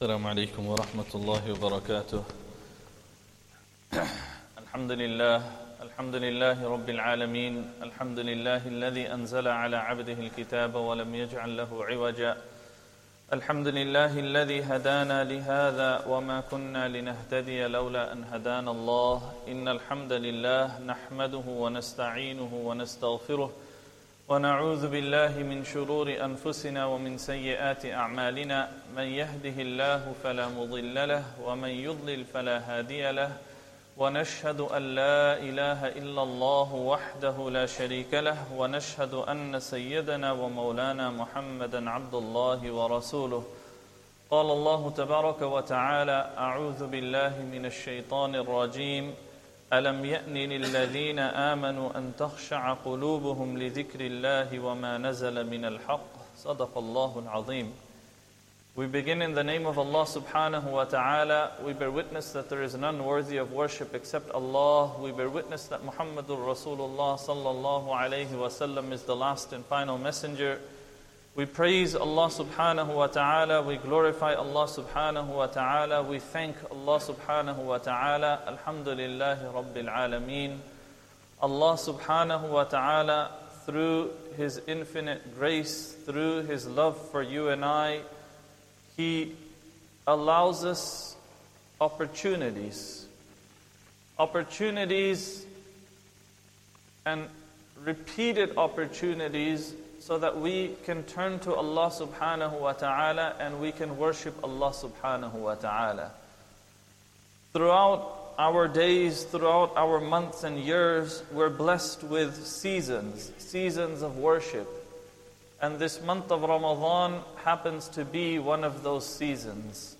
Ramadhan Day 14 Asr Talk
Masjid Adam, Ilford